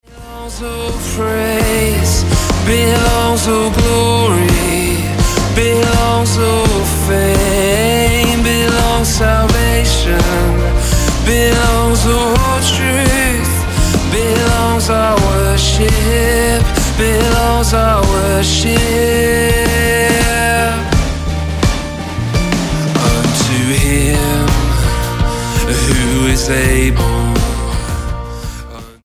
Style: Pop Approach: Praise & Worship